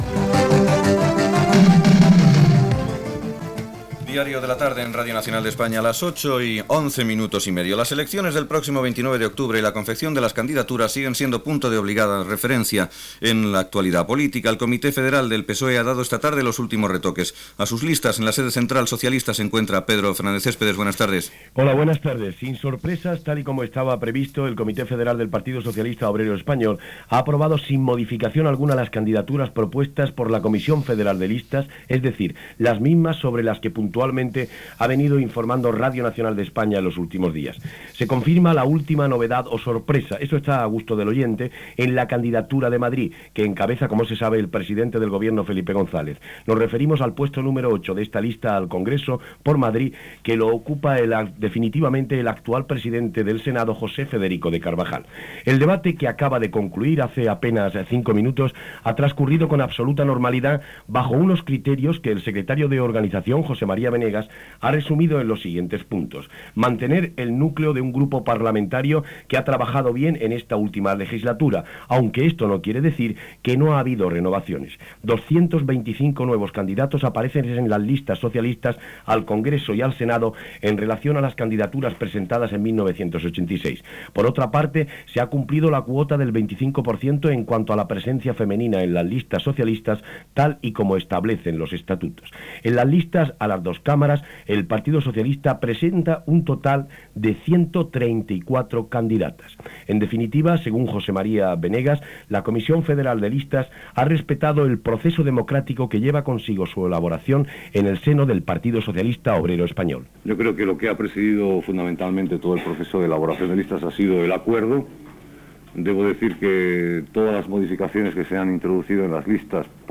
Hora, candidatures a les eleccions del PSOE, Izquierda Unida, posició de la patronal, Ministeri de Defensa, fons estructurals de la Comunitat Europea, Federació Russa, comiat Gènere radiofònic Informatiu